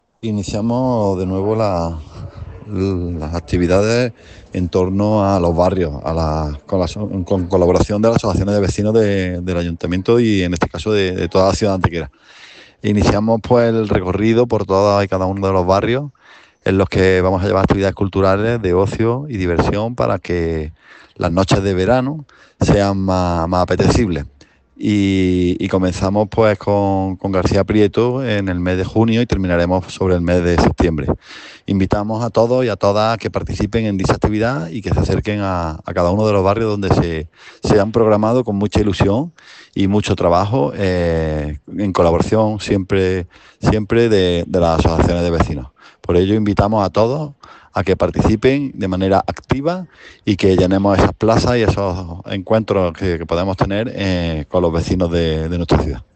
El teniente de alcalde Alberto Arana ha destacado que “iniciamos de nuevo las actividades en torno a los barrios, con colaboración de las asociaciones de vecinos del ayuntamiento y en este caso de toda la ciudad de Antequera.
Cortes de voz